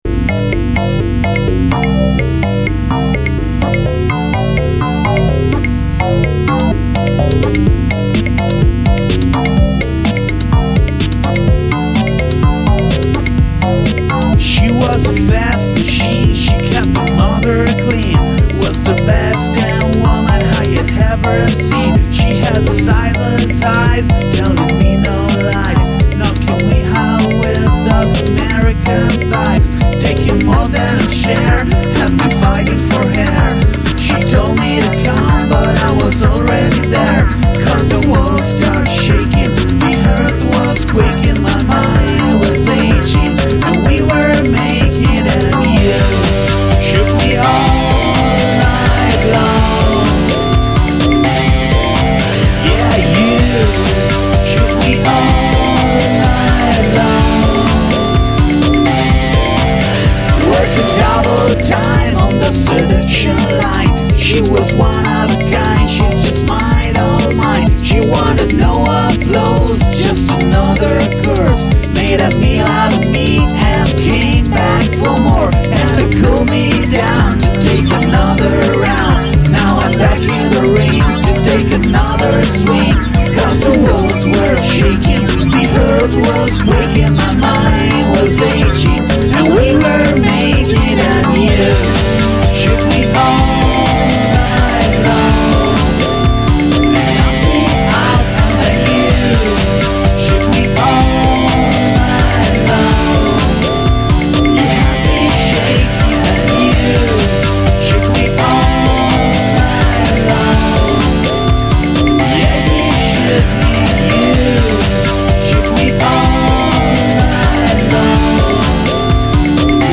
a bizarre cover